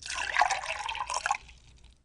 pourMilk1.wav